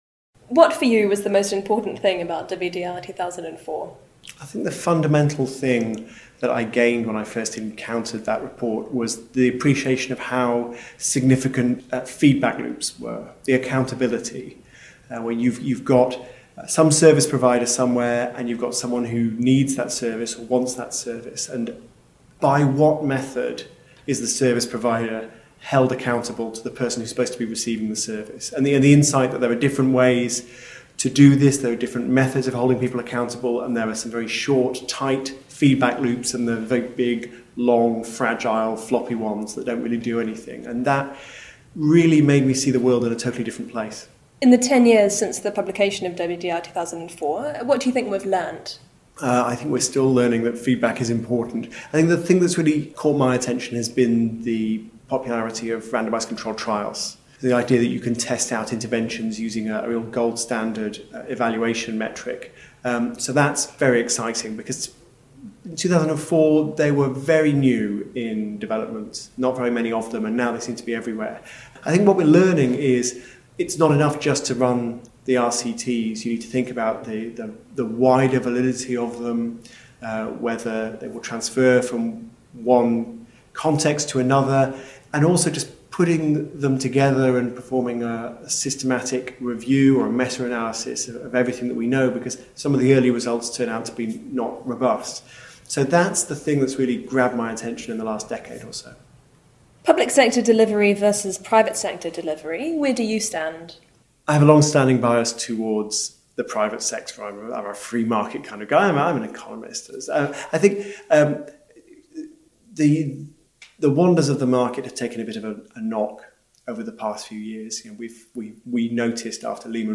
At the 10th anniversary conference for the World Development Report 2004 (Making Services Work for Poor People), we interviewed Financial Times columnist and author Tim Harford about the power of market mechanisms, accountability, and why, when developing public services, we should learn from the private sector.